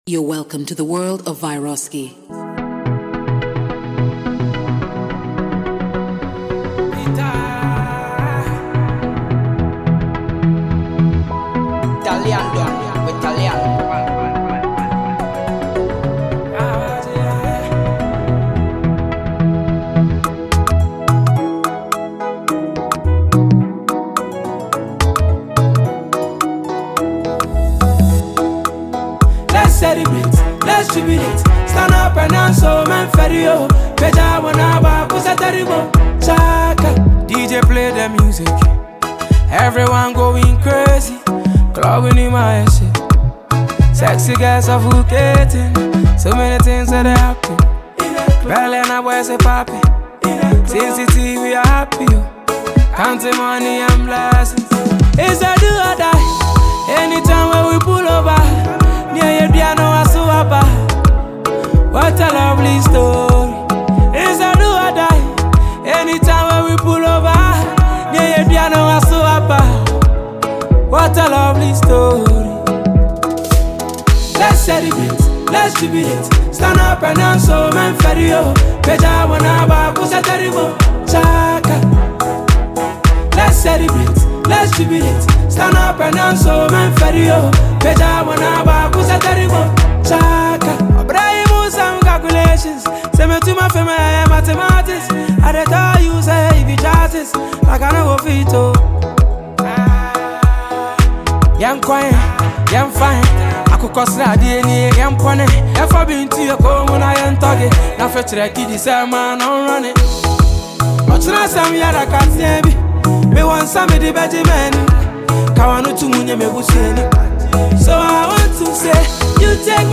emotionally charged record
Through his soulful delivery and relatable storytelling